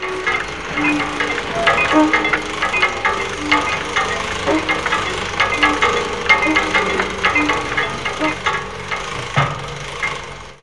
ServoShrt.wav